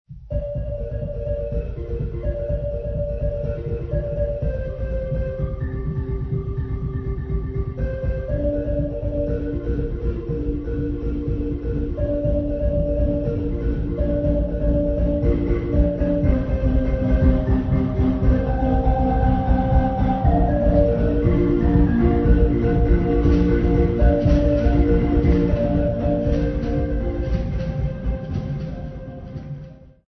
St Patricks' Church Choir
Folk music
Field recordings
sound recording-musical
Participants in the Xhosa Church Music Workshop perform hymn with Marimba accompaniment.
96000Hz 24Bit Stereo